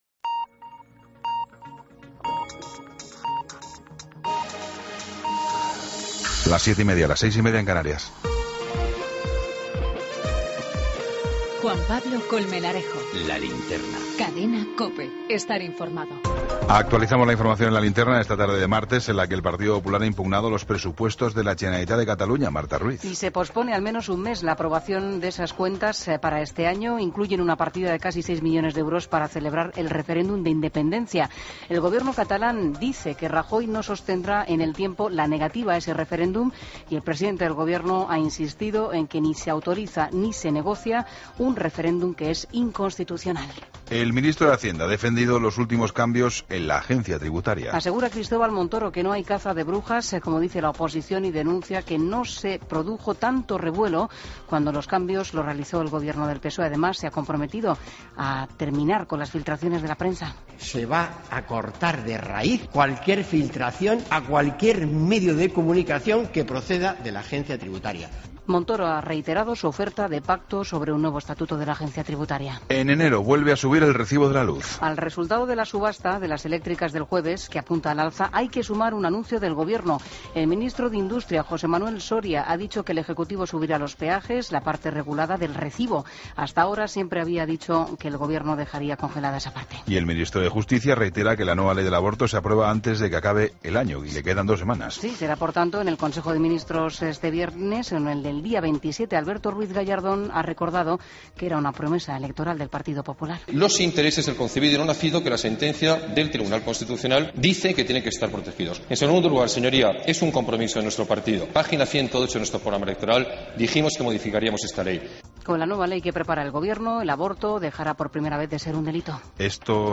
AUDIO: Toda la información con Juan Pablo Colmenarejo. Entrevista